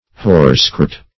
Search Result for " horsecart" : The Collaborative International Dictionary of English v.0.48: horsecart \horse"cart`\ n. 1.